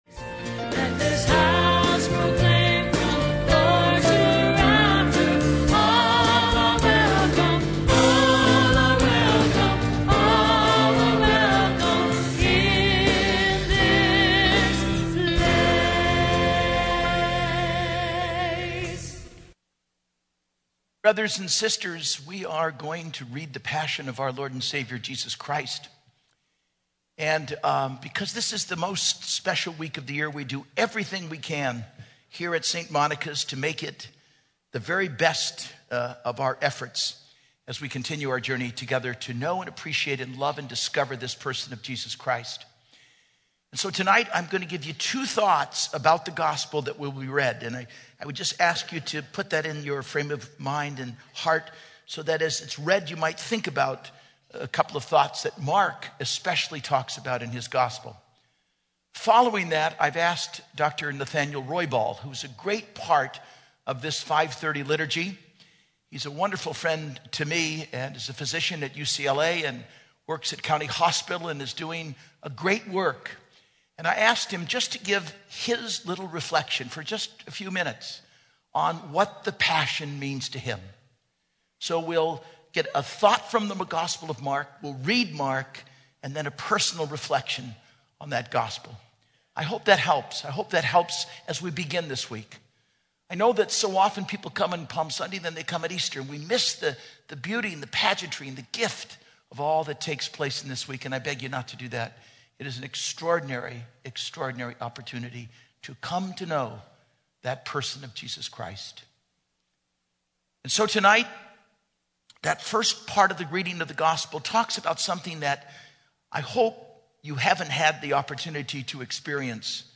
Homily - 4/1/12 - Palm Sunday